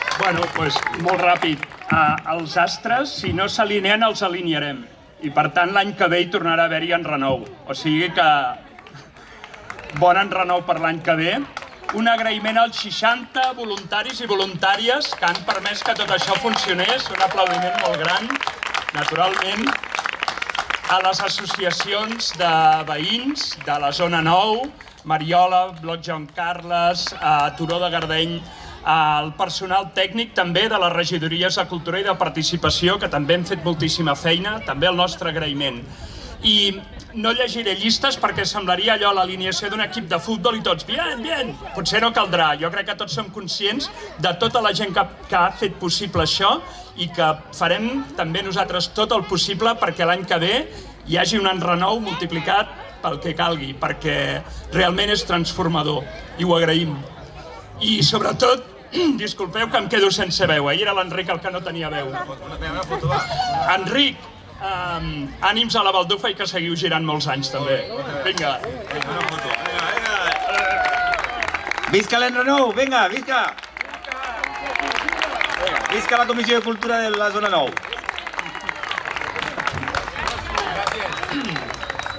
Tall de veu J. Rutllant